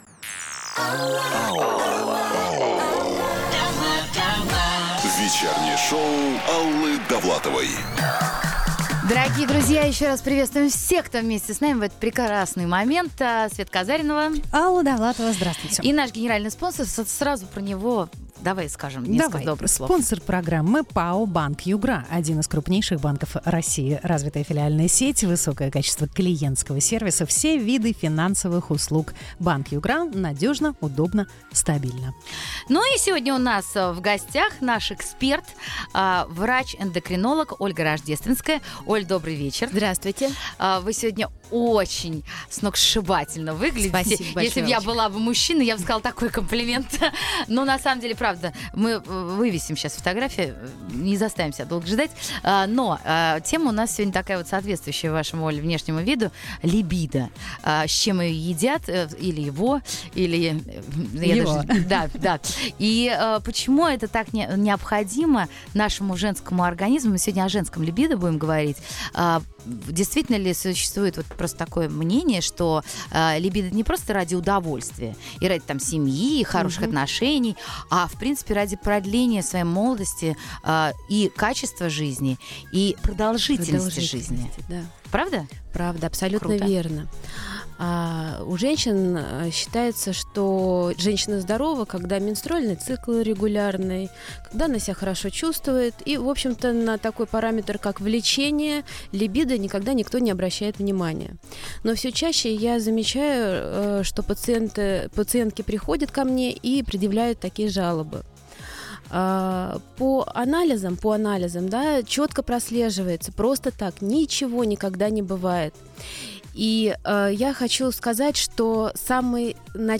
интервью
Дата: 10.11.2016 Источник: Русское радио.